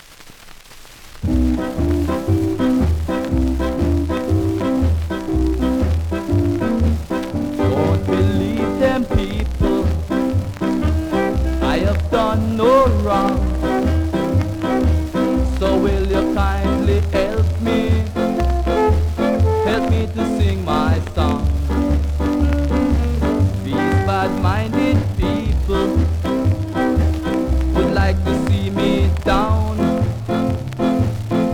プレス起因のノイズもあり。